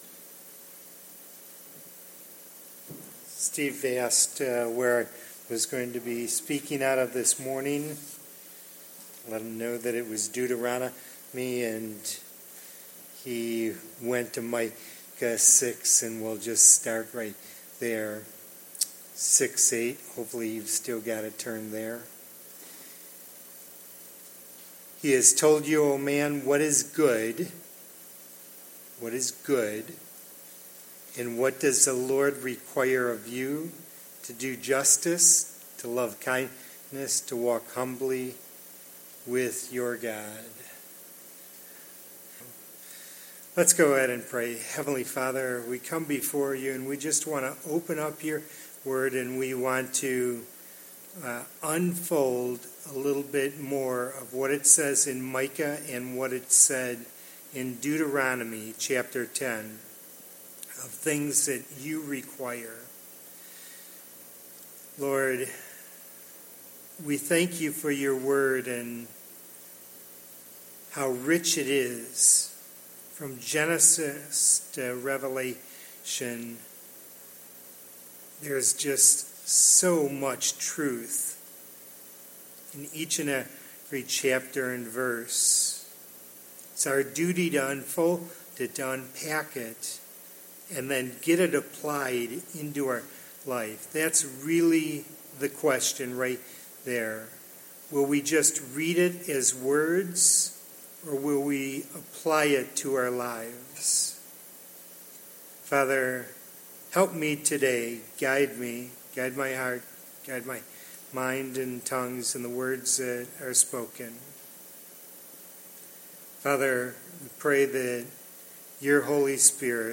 Passage: Deuteronomy 10:12, Micah 6:8 Service Type: Morning Worship « Preserving Unity in the Church